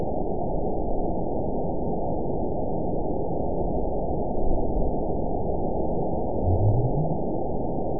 event 912222 date 03/21/22 time 12:23:21 GMT (3 years, 2 months ago) score 9.62 location TSS-AB01 detected by nrw target species NRW annotations +NRW Spectrogram: Frequency (kHz) vs. Time (s) audio not available .wav